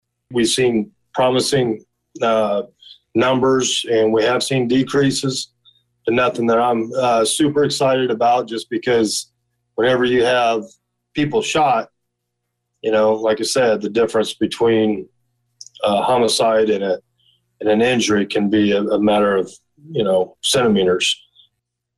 So says Danville Police Chief Christopher Yates who issued a report Tuesday evening to the Public Services Committee of the Danville City Council….